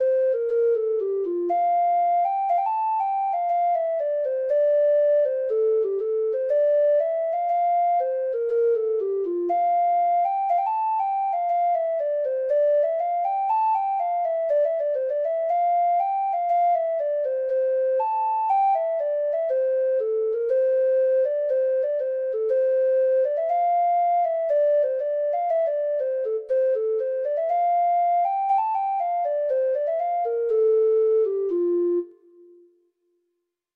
Treble Clef Instrument version
Traditional Music of unknown author.